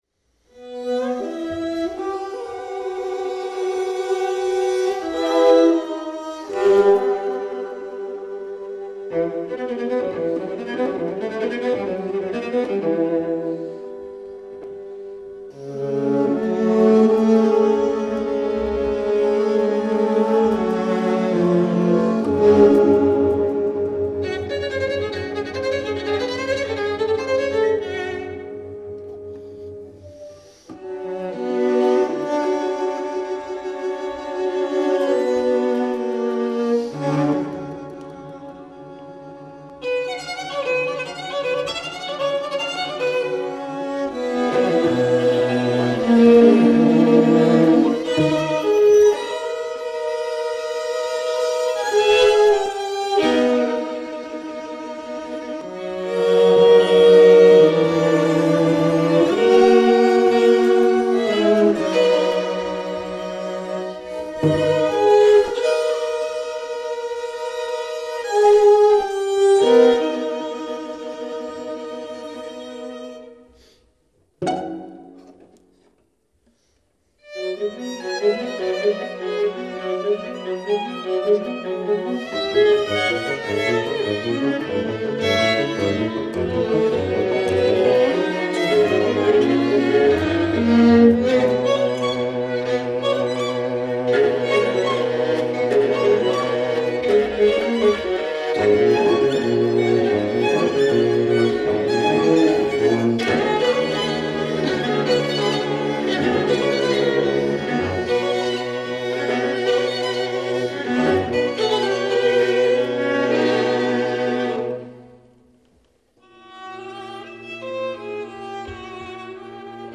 Wilton’s Music Hall